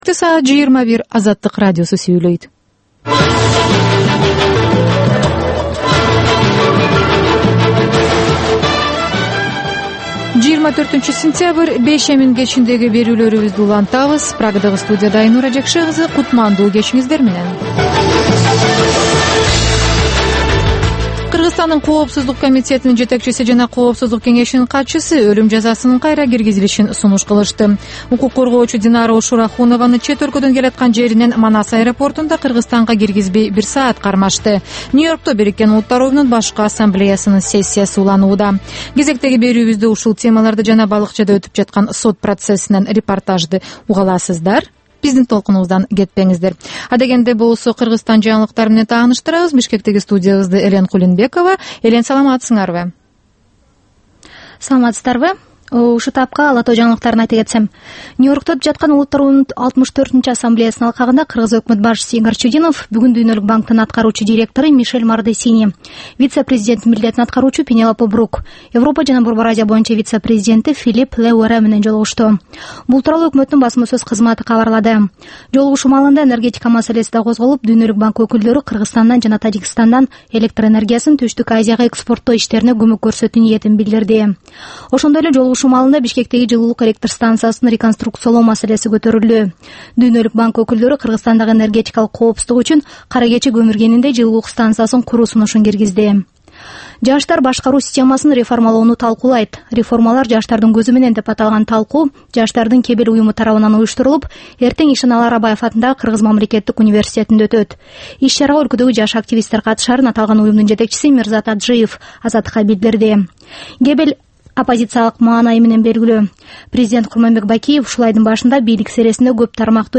Бул кечки үналгы берүү жергиликтүү жана эл аралык кабарлардан, репортаж, маек, баян жана башка берүүлөрдөн турат. "Азаттык үналгысынын" бул кечки берүүсү ар күнү Бишкек убактысы боюнча саат 21:00ден 21:30га чейин обого түз чыгат.